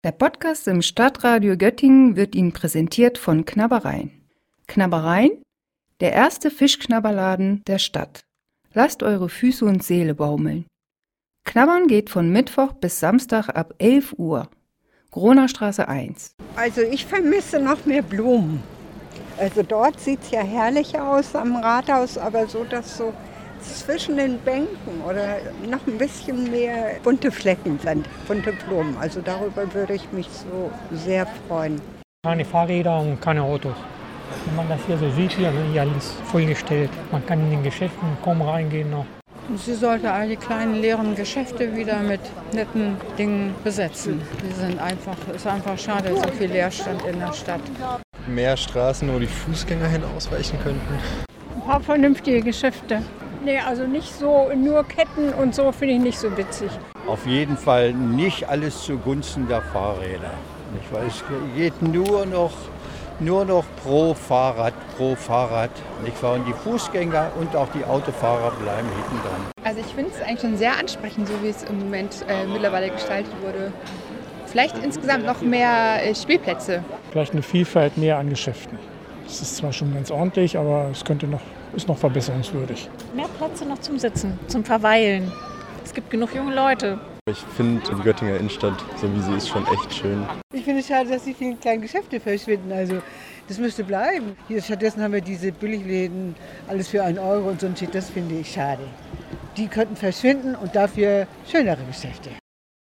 Die Kirchenglocken läuten und man hört im Hintergrund das Glockenspiel aus der Langen Geismarstraße.